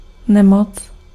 Ääntäminen
France : « une passion »: IPA: [yn pa.sjɔ̃]